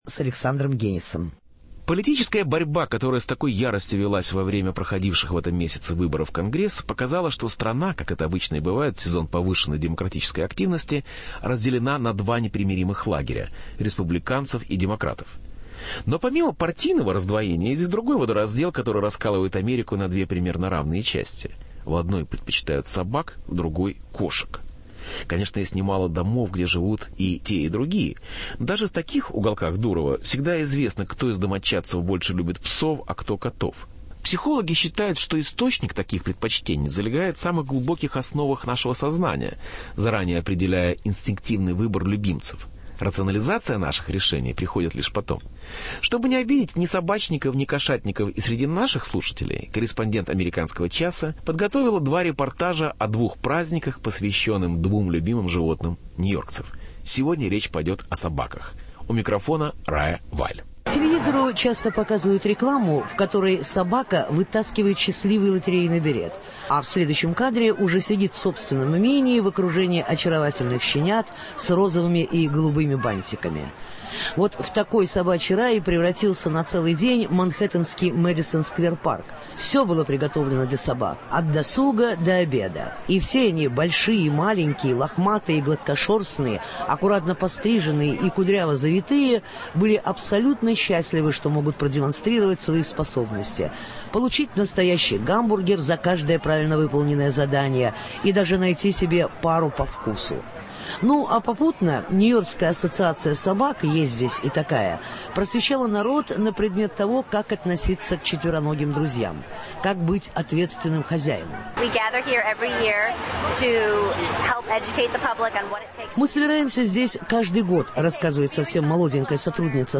Собаки Нью-Йорка. Репортаж с фестиваля собаководов